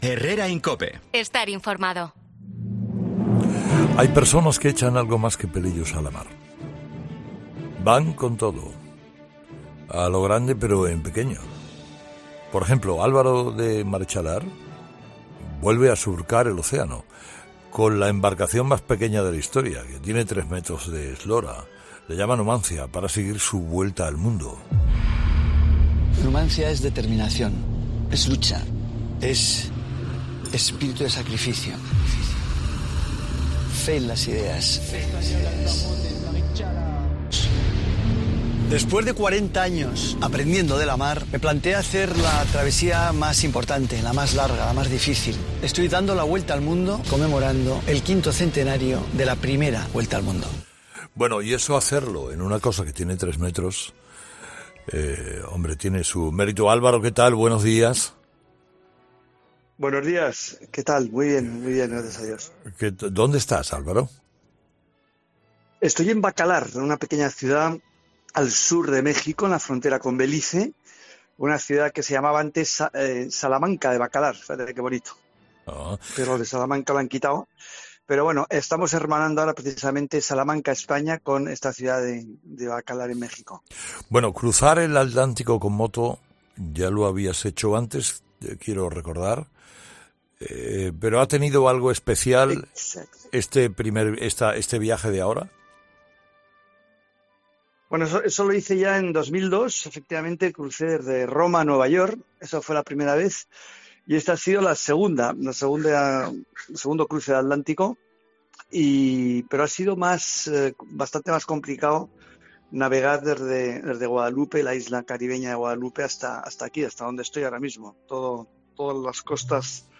El aristócrata ha pasado por 'Herrera en COPE' para hablar de su nueva travesía, conmemorar el quinto centenario de la vuelta al mundo en una...
También ha afirmado en la charla con Carlos Herrera , que lleva tres años sin volver a España.